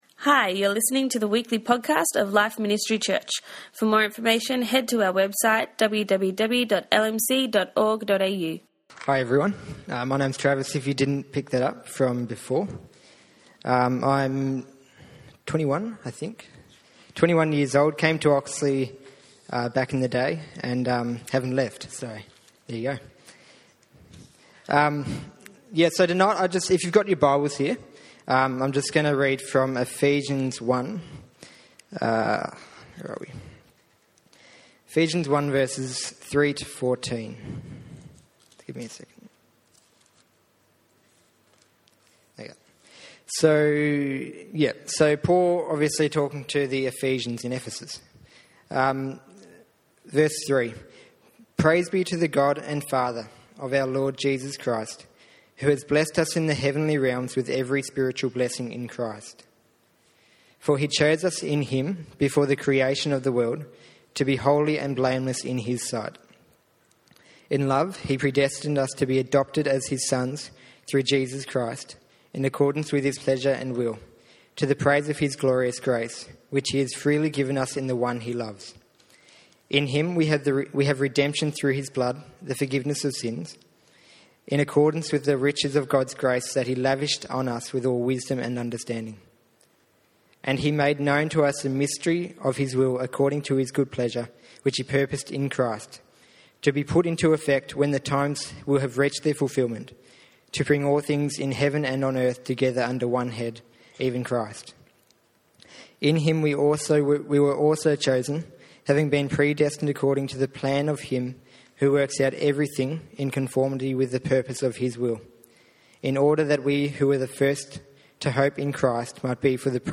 Youth and YA Service